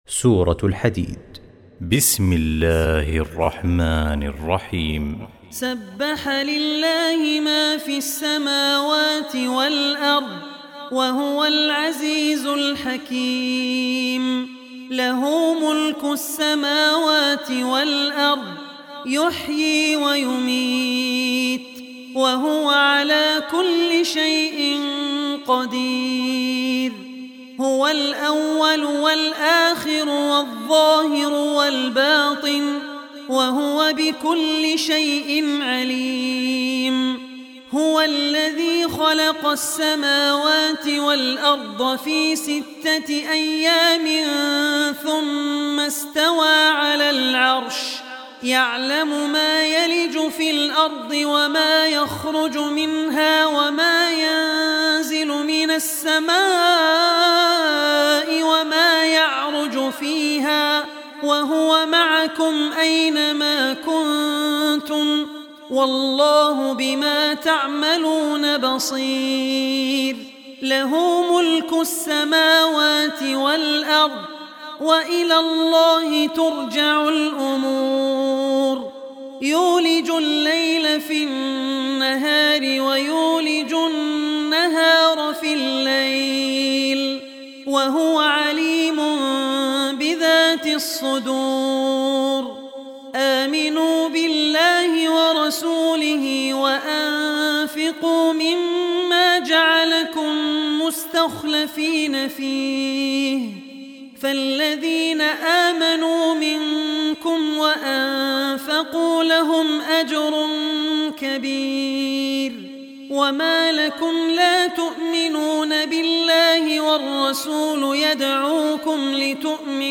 Surah al-Hadid Recitation by Abdur Rehman Al Ossi
Surah al-Hadid, listen online mp3 arabic recitation, recited by Abdur Rehman Al Ossi.